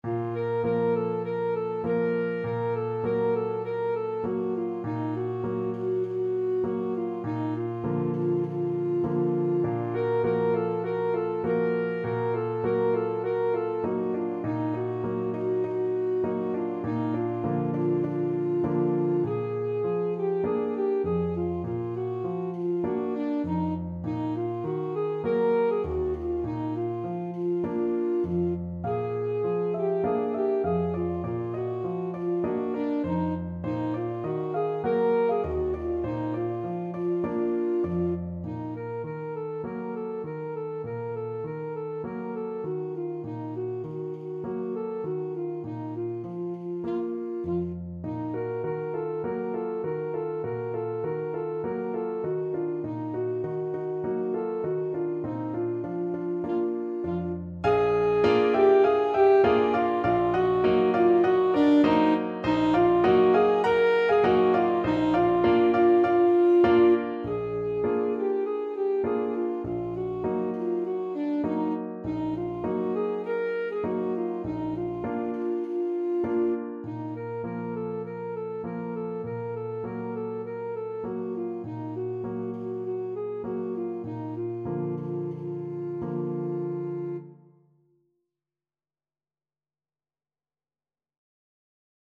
Alto Saxophone
Traditional Music of unknown author.
4/4 (View more 4/4 Music)
Moderato
World (View more World Saxophone Music)